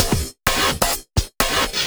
Index of /VEE/VEE2 Loops 128BPM
VEE2 Electro Loop 369.wav